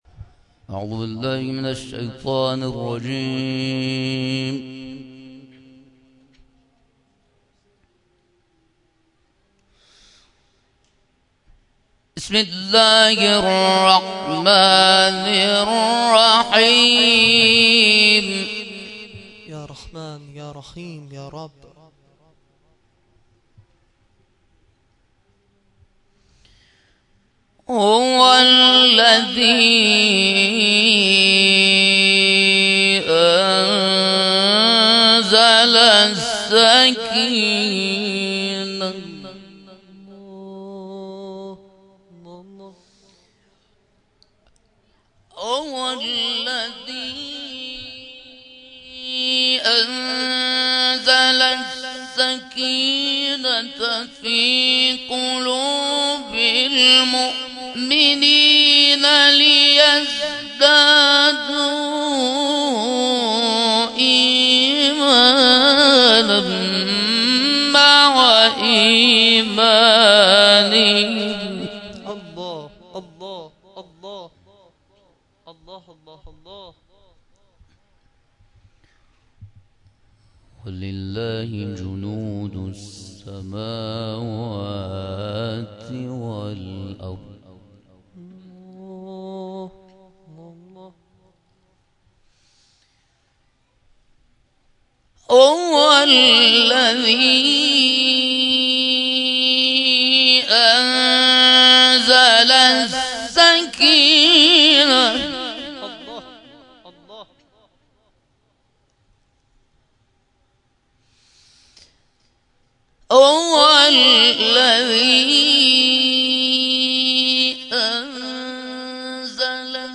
در این مراسم علاوه بر مرثیه‌خوانی ذاکران اهل بیت عصمت و طهارت، قاریان ممتاز و بین‌المللی کشورمان نیز به تلاوت پرداختند.
تلاوت